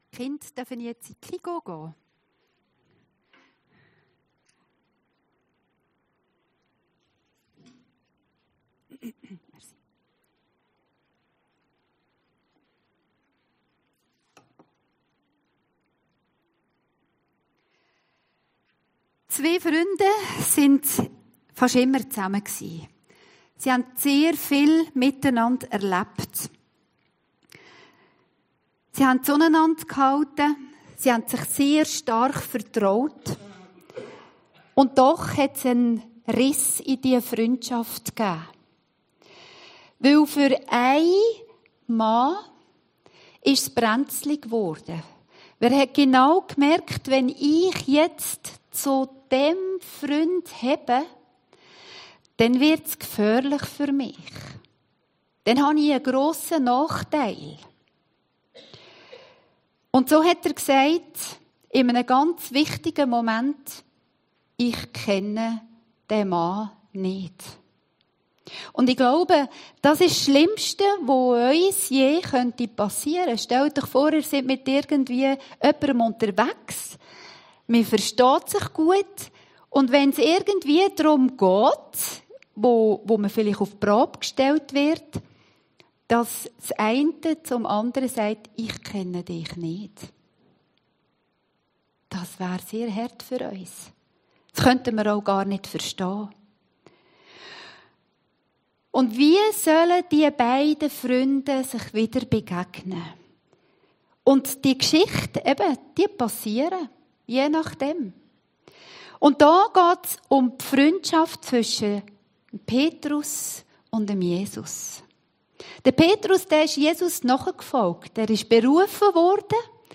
Predigten Heilsarmee Aargau Süd – Petrus, liebst du mich?